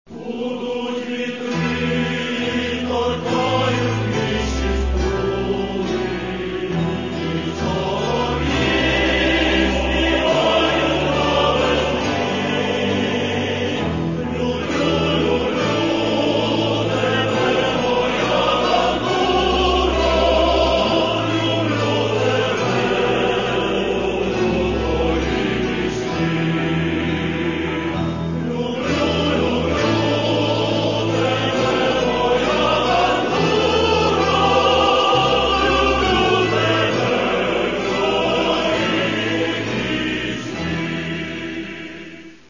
Catalogue -> Folk -> Bandura, Kobza etc